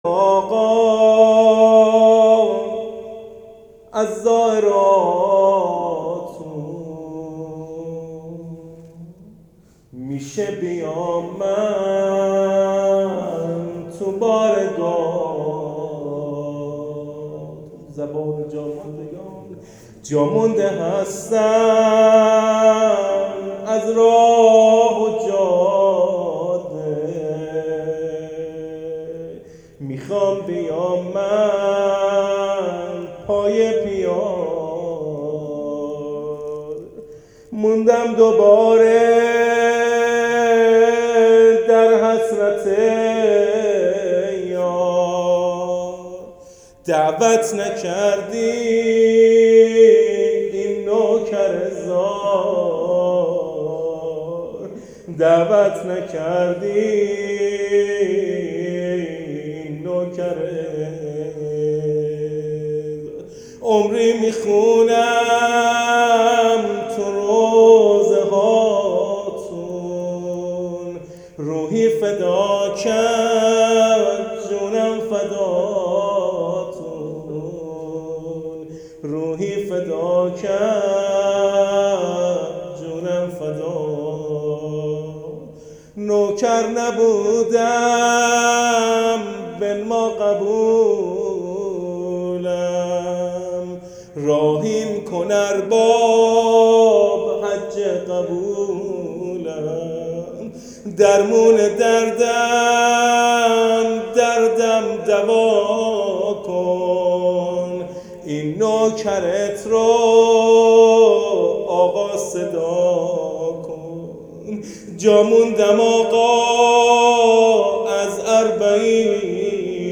مرثیه جا ماندگان اربعین